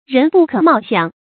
人不可貌相的讀法